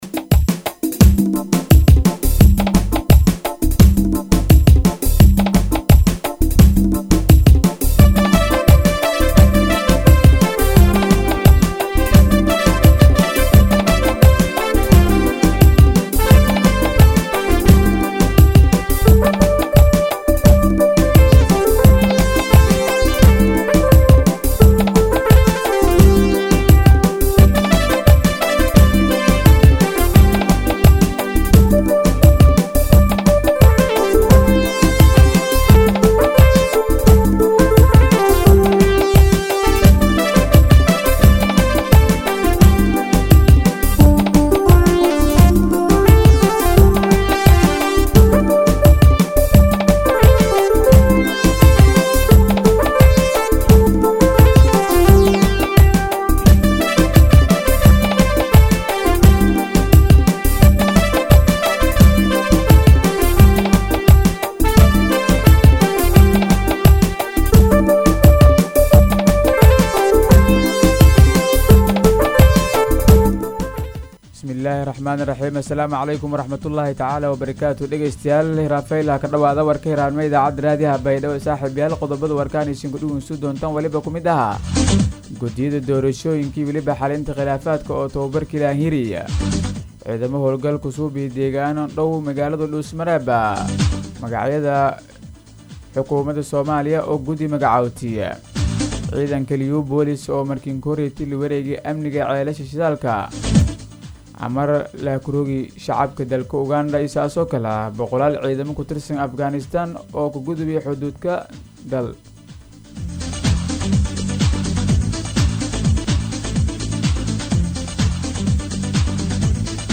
DHAGEYSO:- Warka Subaxnimo Radio Baidoa 4-7-2021